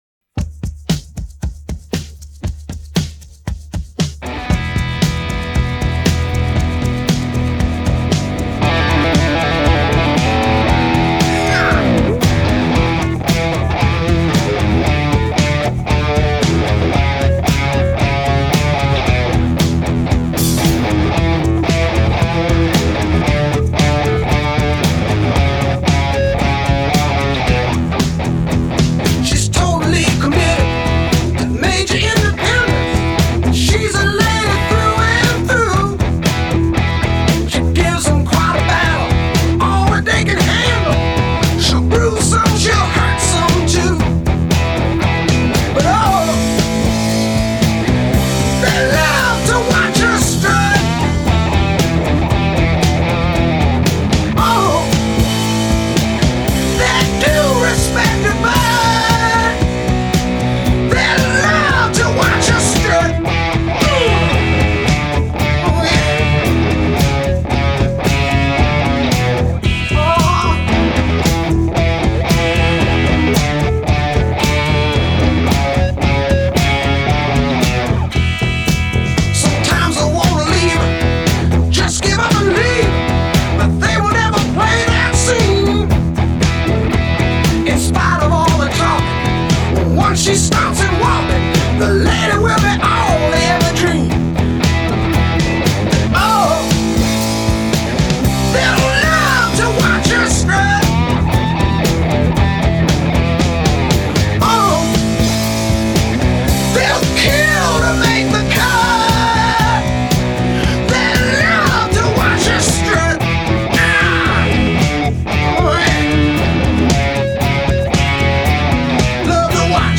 It has a cool guitar riff that has always stuck in my head.